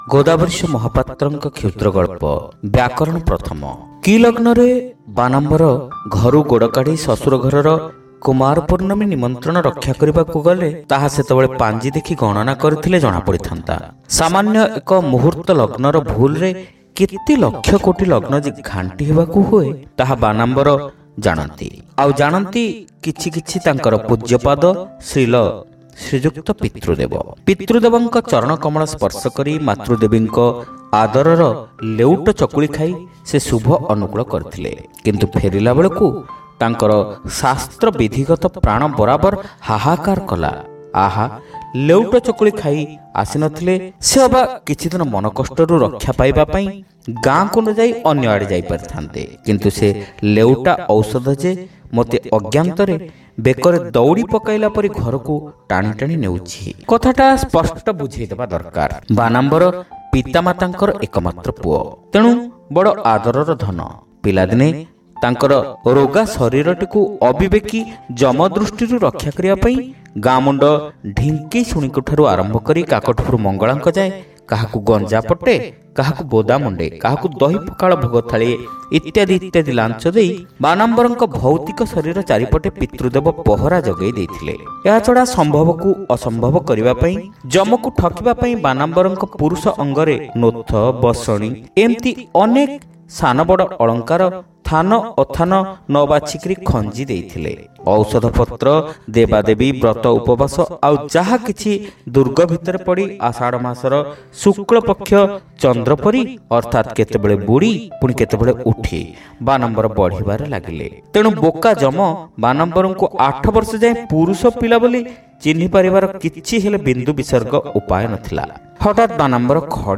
Audio Story : Byakarana Prathama